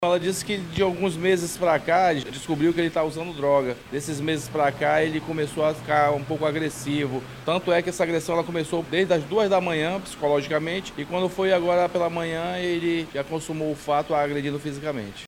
SONORA-2-TENTATIVA-FEMINICIDIO-.mp3